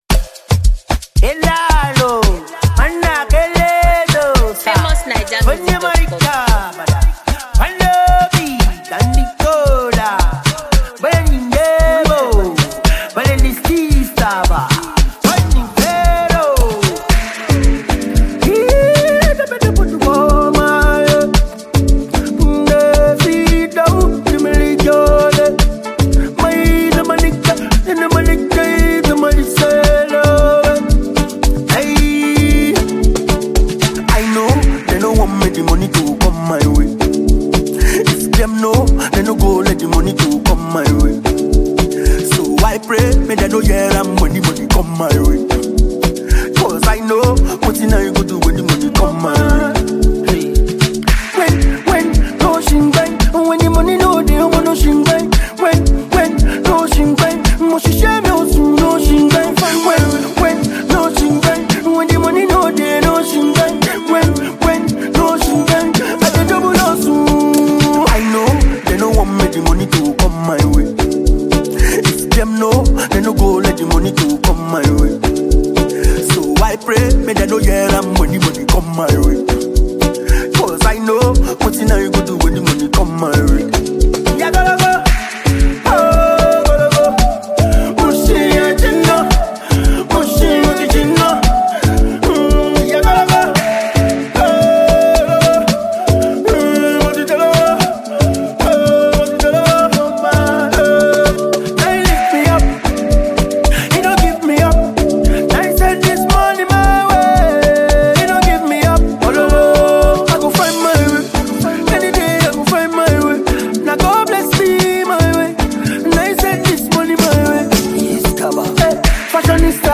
a veteran vocalist